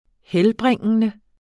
Udtale [ ˈhεlˌbʁεŋˀənə ]